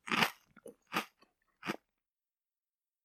Eat_Chewy.mp3